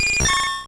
Cri de Papilusion dans Pokémon Rouge et Bleu.